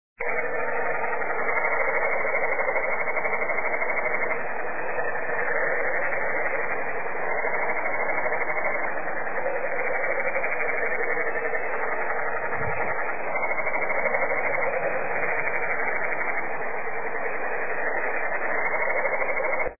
canyon treefrogs also are talking it up, in their nonstop, goat-like croaks.
canyon_treefrogs.mp3